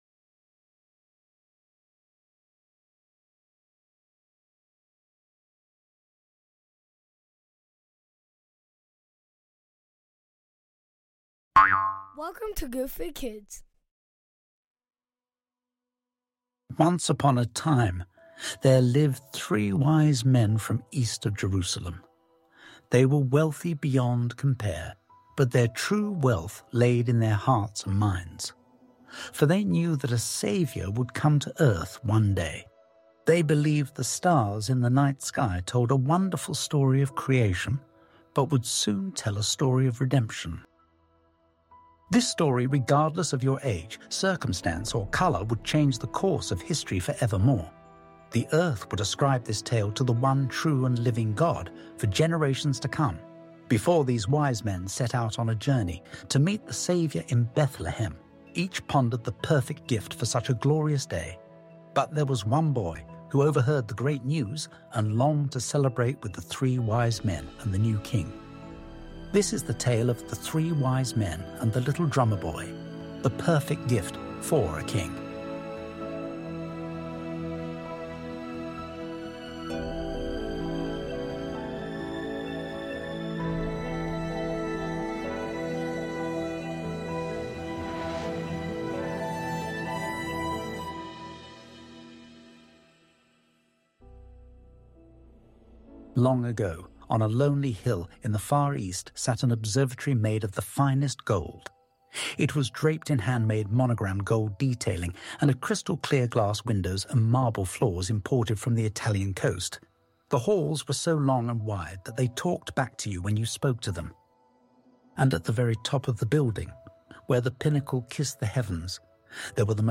Join us on an enchanting journey with Good Food Kids as we narrate the timeless story of the three wise men and the little drummer boy. Set in an opulent observatory in the east, discover how a poor orphaned boy finds hope and purpose through his encounter with the wise men.